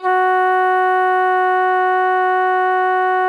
FluteClean2_F#2.wav